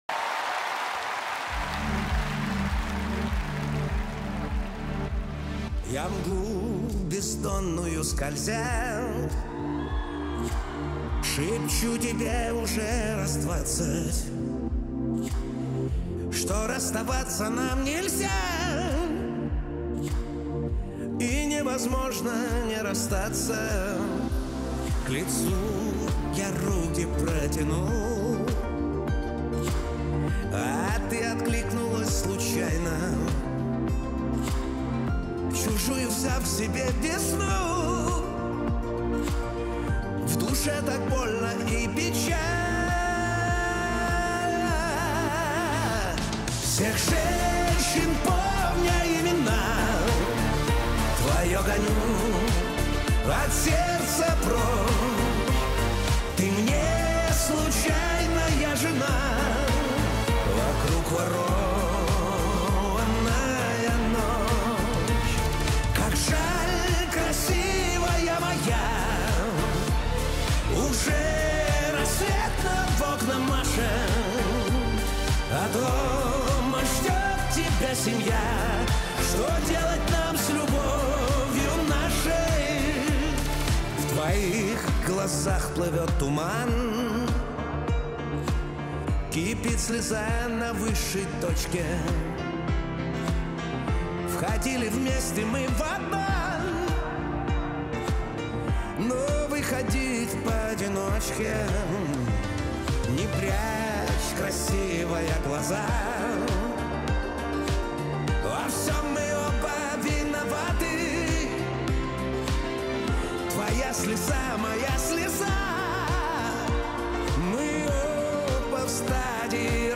Русский шансон
Романтичная песня про женщин